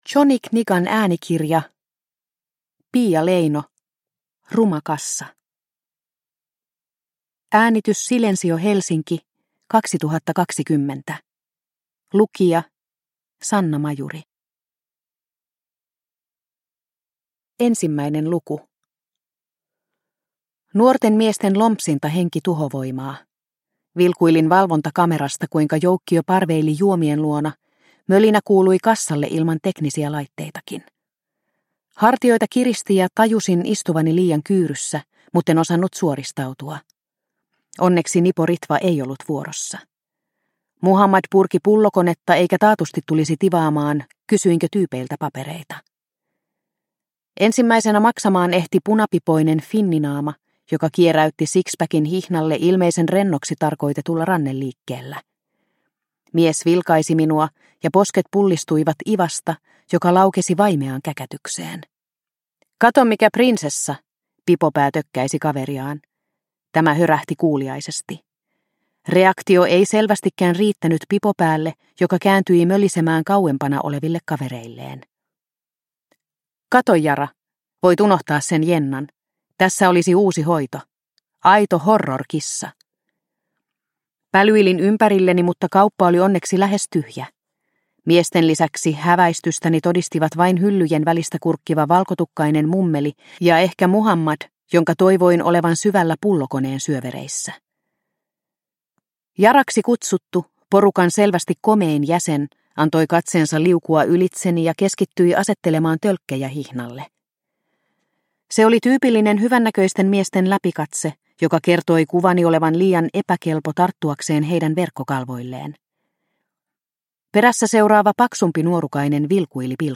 Ruma kassa – Ljudbok – Laddas ner